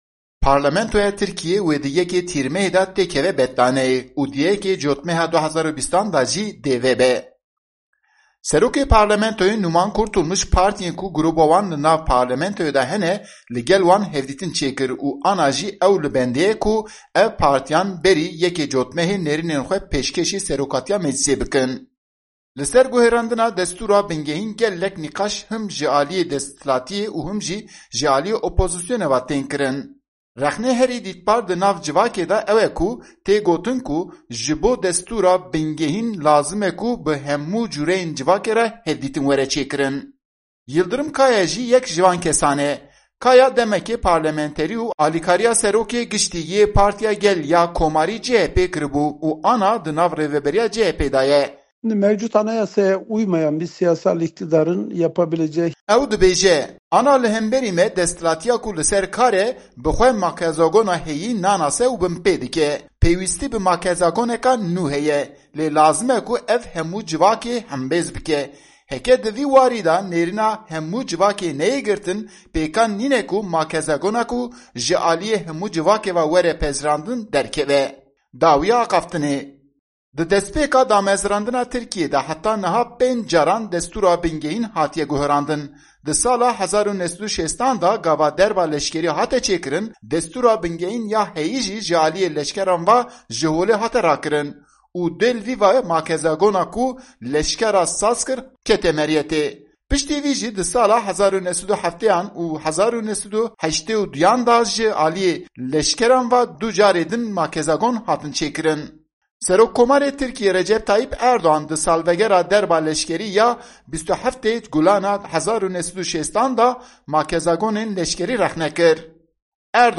Raporta Enqerê - Qanûna Bingehîn ya Nû li Tirkiyê.mp3